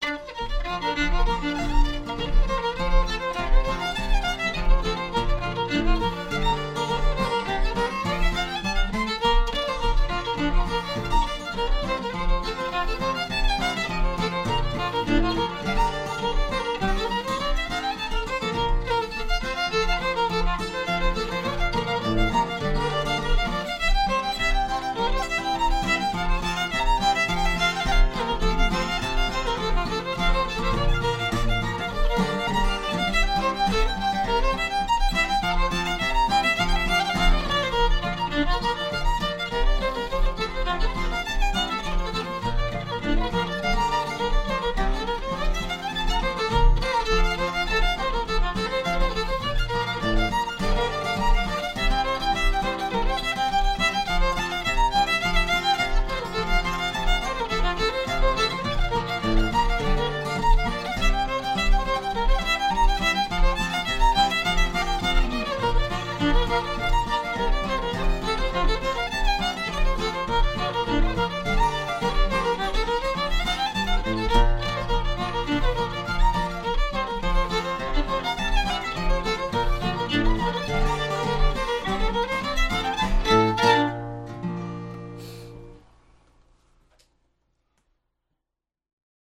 gtr